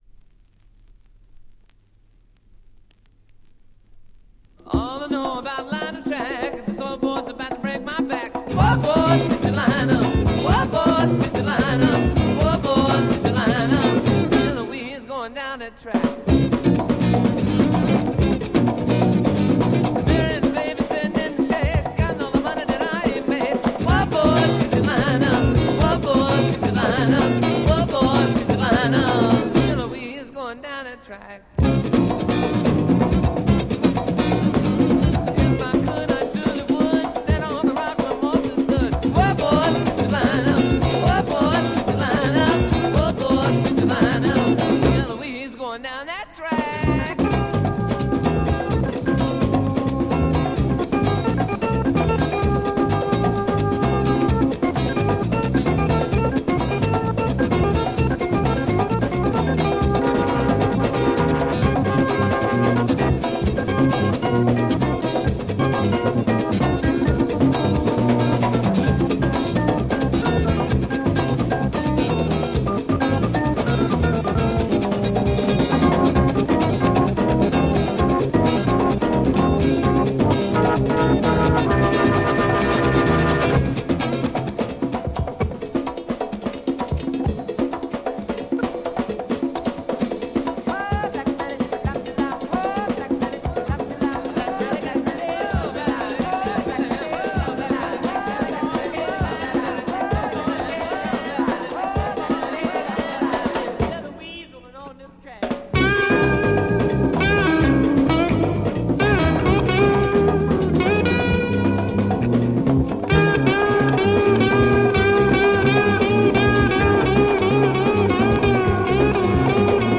Great Hippy Funk bongoes rock dancer